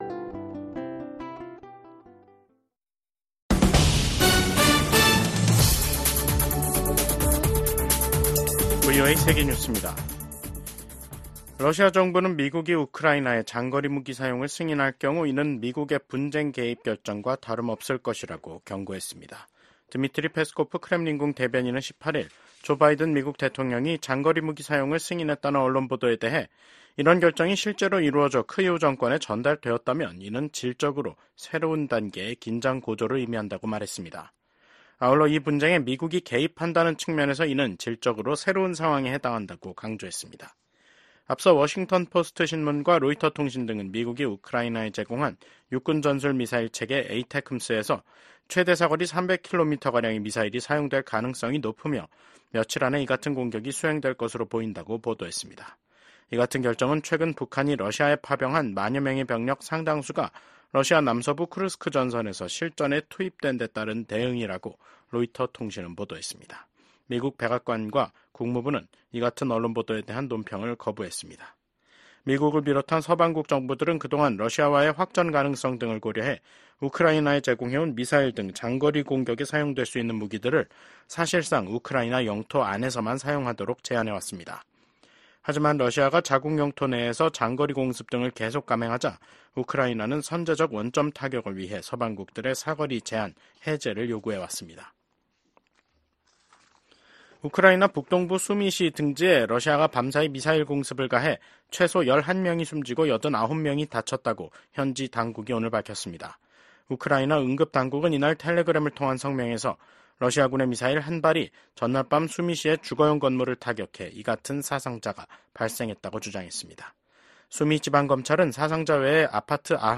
VOA 한국어 간판 뉴스 프로그램 '뉴스 투데이', 2024년 11월 18일 2부 방송입니다. 미국과 한국, 일본의 정상이 북한군의 러시아 파병을 강력히 규탄했습니다.